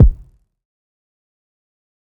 TC2 Kicks5.wav